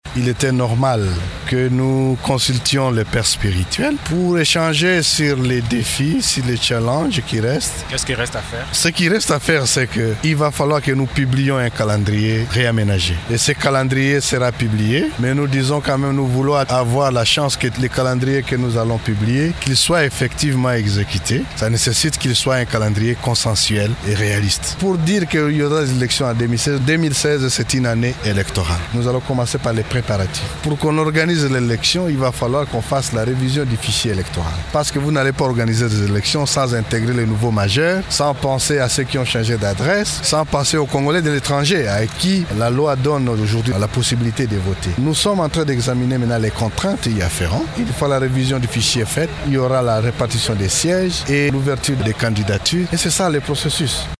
Vous pouvez écouter un extrait des propos de Corneille.